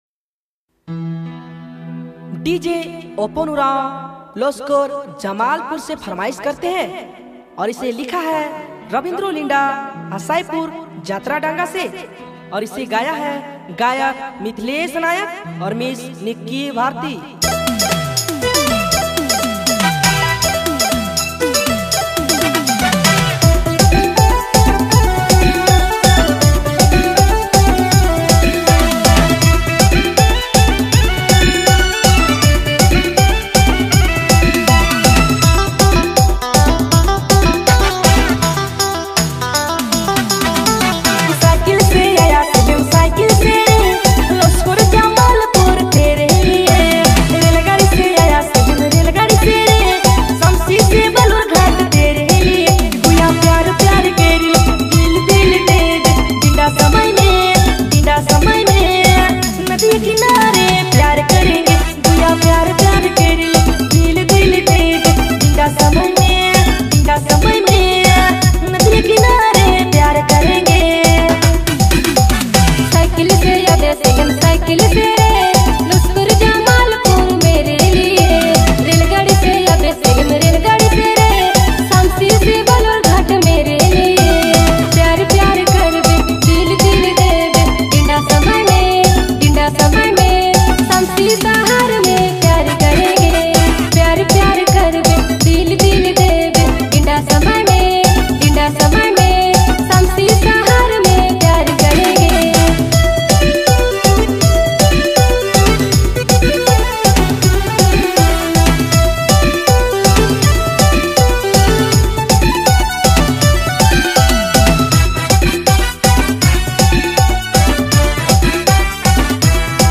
All Dj Remix